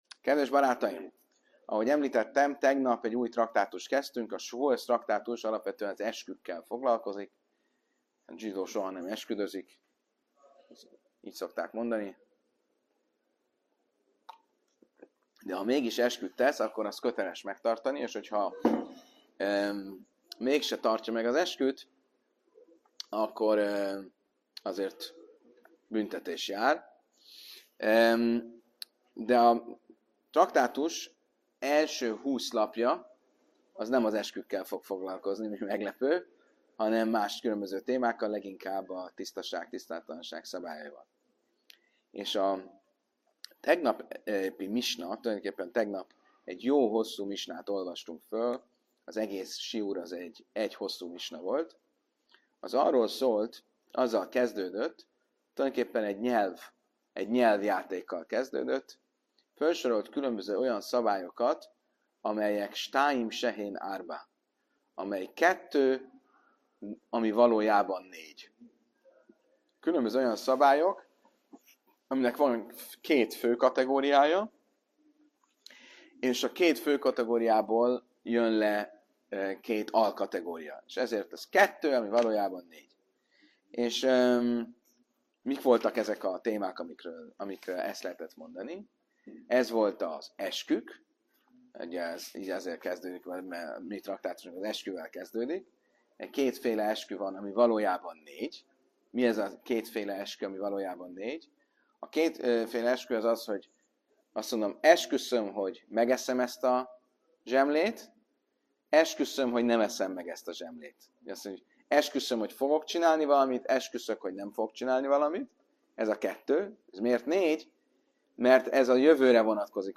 Az előadás célja ennek a formulának a mélyebb vizsgálata, illetve annak feltérképezése, hogy ki lehet a misna szerzője, és vajon valóban minden felsorolt eset jogi értelemben is egyformán „kettő, ami négy”-nek számít-e. A „kettő, ami négy” struktúra Négy fő terület szerepel ebben a formulában: Eskük : két jövőre vonatkozó (megeszem / nem eszem), két múltra vonatkozó (megettem / nem ettem) eskü.